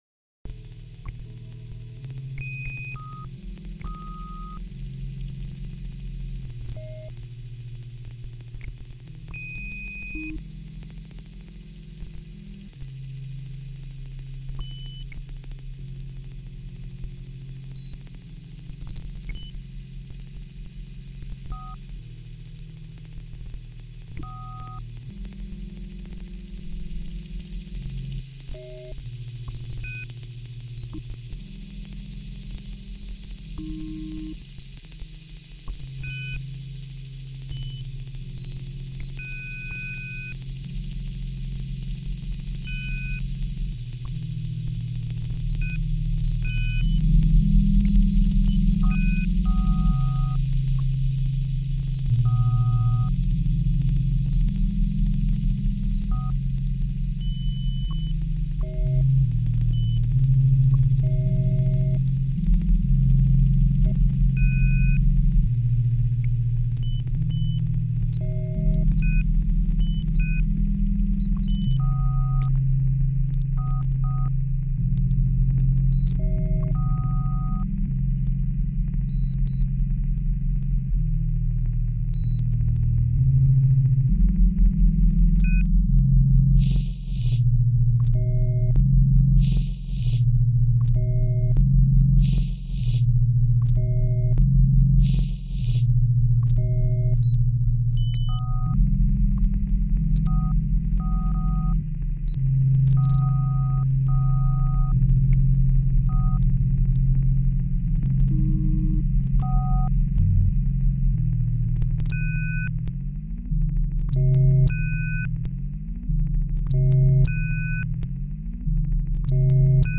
introspective and spatial ambient, of the dark kind